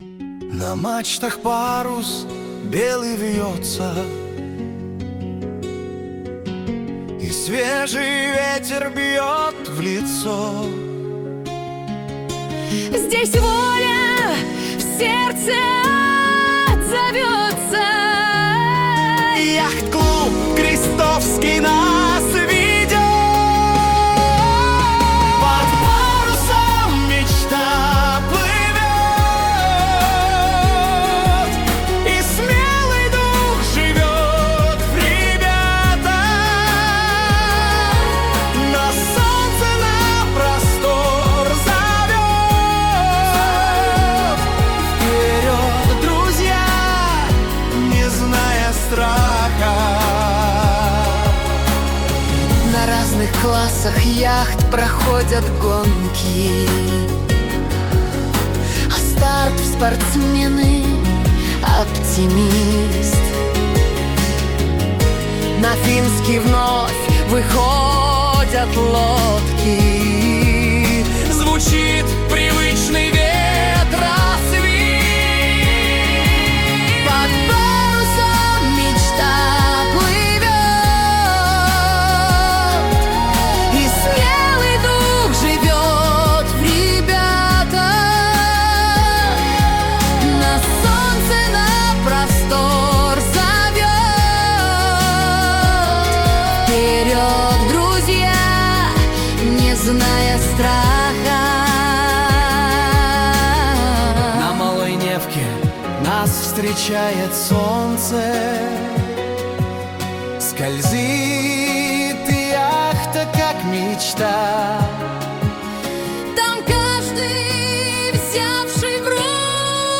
При поддержке родителей, с помощью ИИ, спортсмены создали песню — настоящий гимн школы «Крестовский остров»!